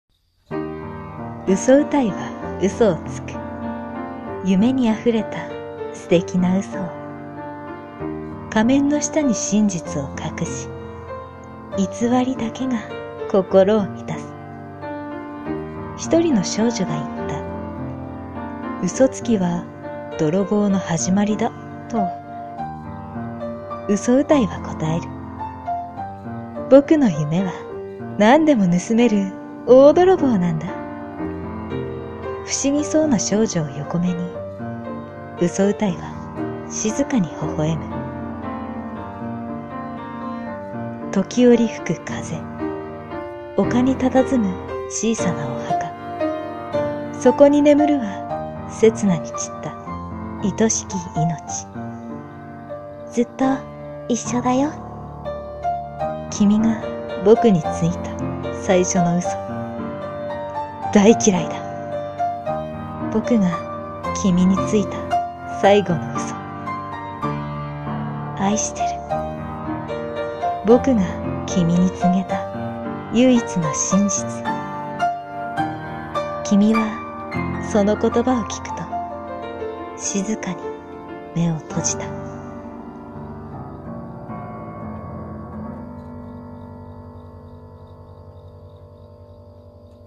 【声劇台本】嘘歌い